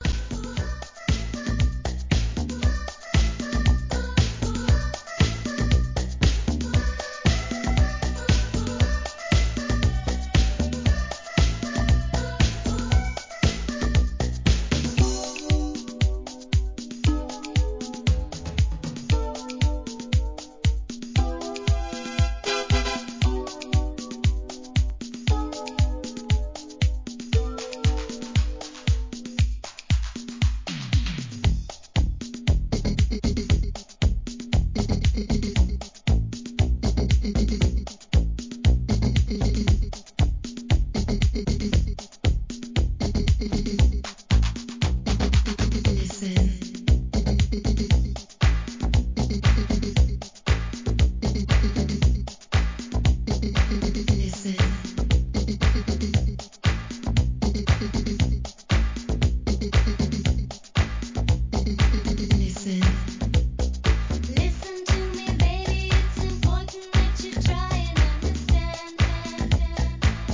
店舗 数量 カートに入れる お気に入りに追加 1988 GARAGE HOUSE!!